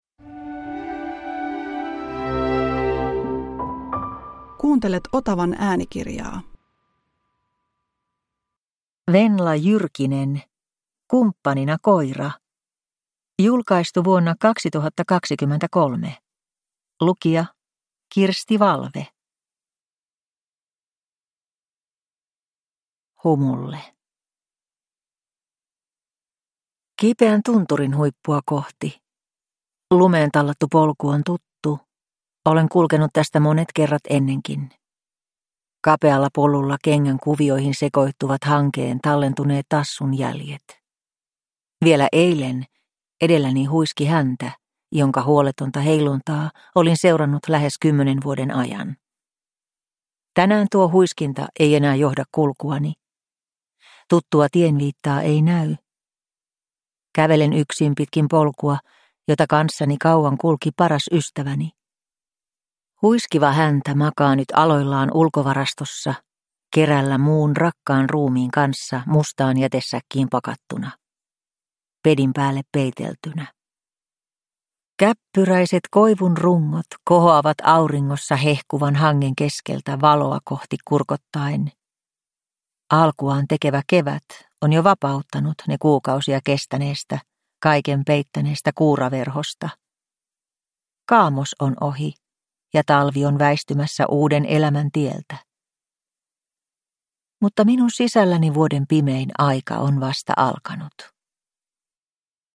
Kumppanina koira – Ljudbok – Laddas ner